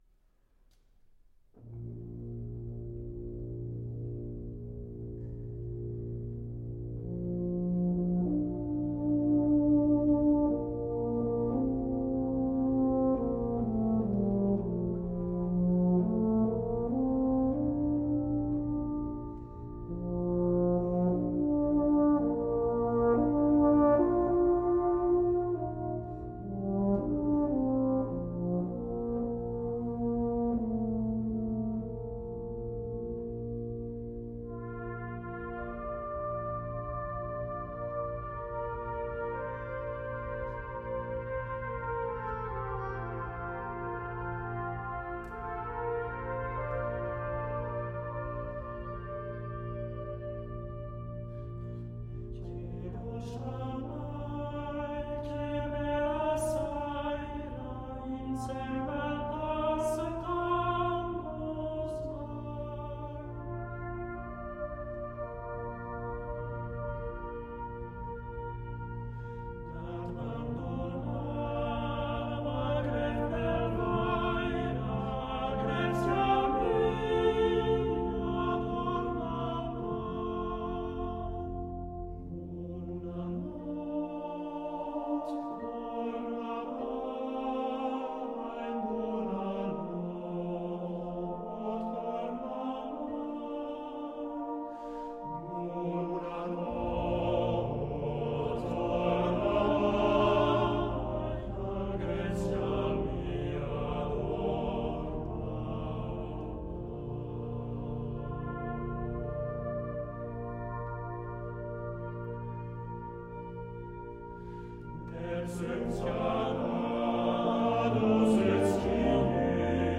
Volkslied aus dem Unterengadin
für Brass Ensemble arrangiert
Besetzung: Ten Piece